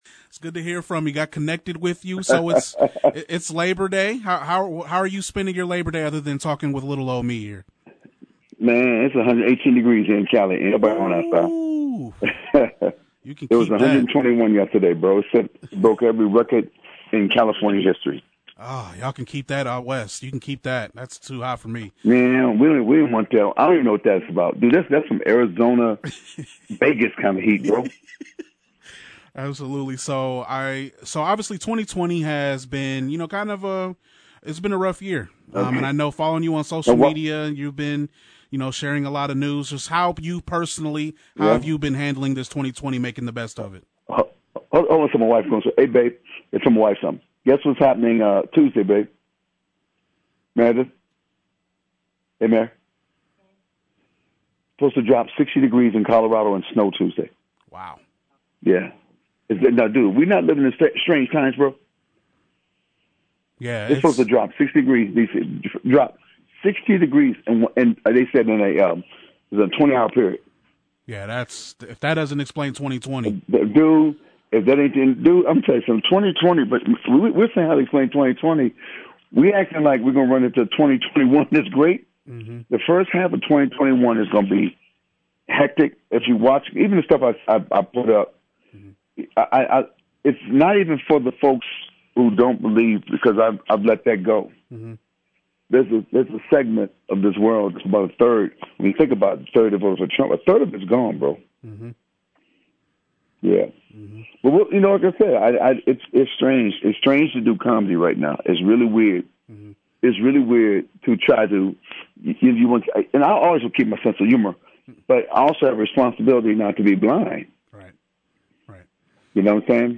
Interview with Sinbad